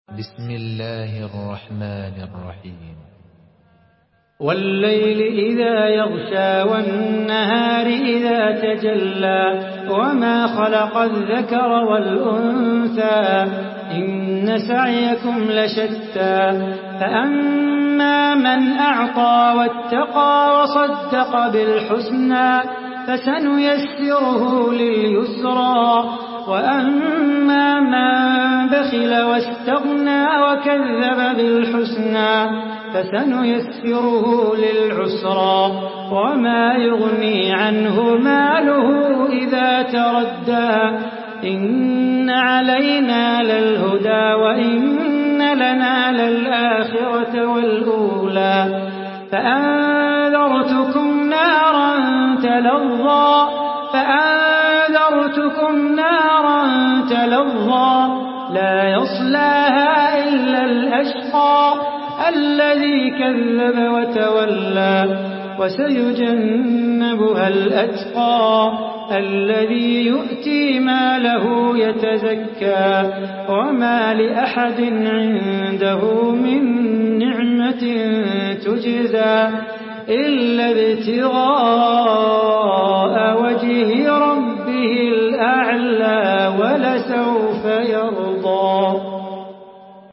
Surah Leyl MP3 by Salah Bukhatir in Hafs An Asim narration.
Murattal Hafs An Asim